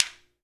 Clap28.wav